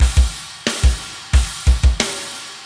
OVERDRIVE MUSIC - Boucle de Batteries - Drum Loops - Le meilleur des métronomes
METAL
Half time - China
Straight / 180 / 1 mes